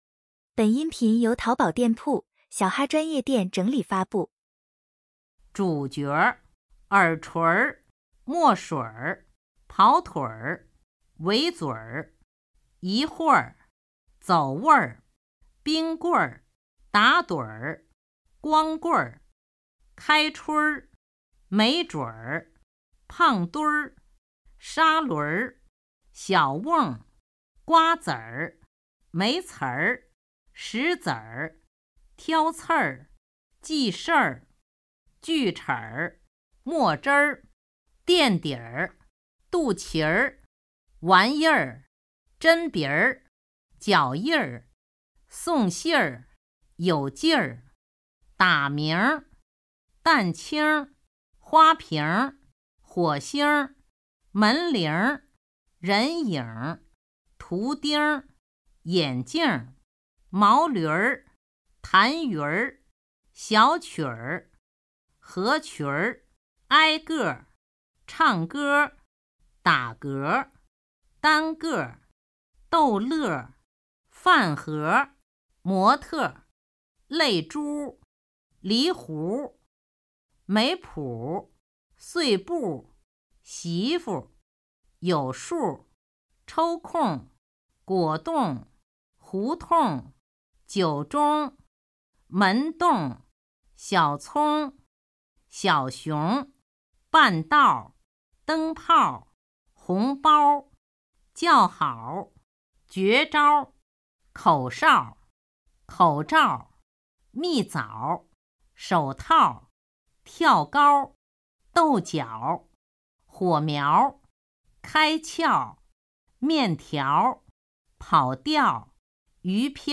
儿化音101到200音频.mp3